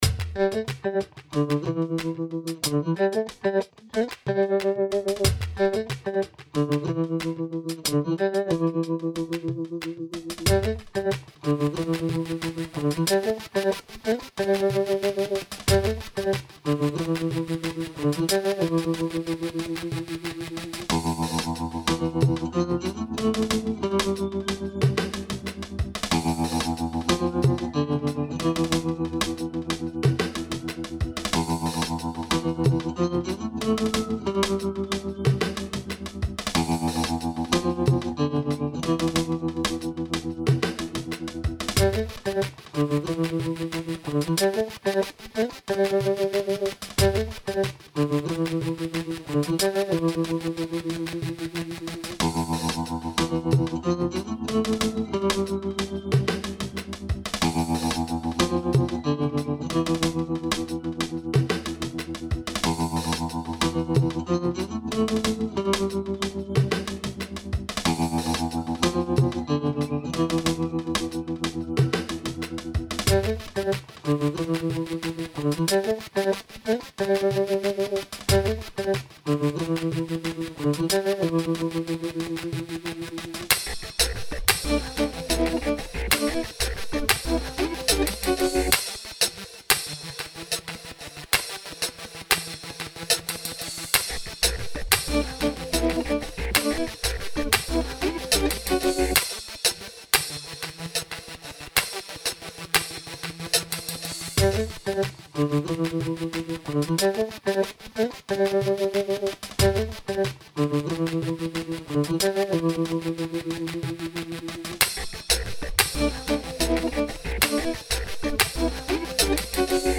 brighter and warmer stufff